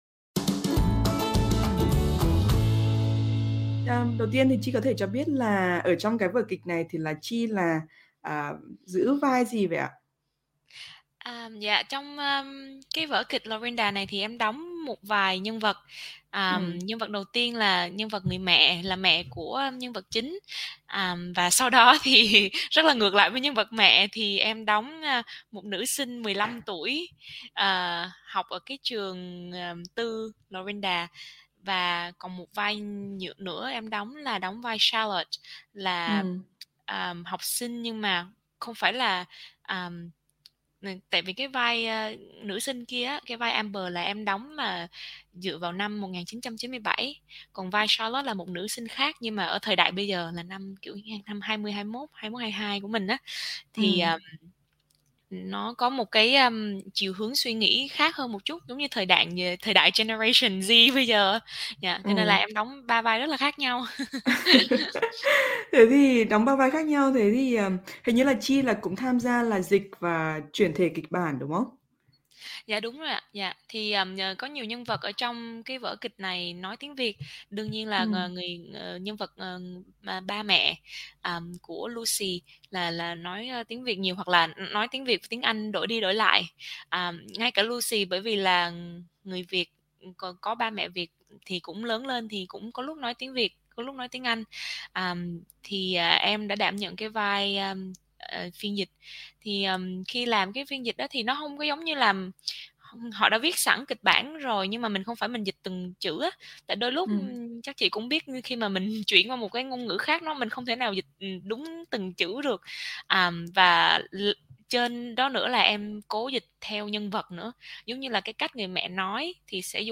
Xin mời quý vị nghe toàn bộ cuộc trò chuyện.
vietnamese-weekend-chat-laurinda-play-0508.mp3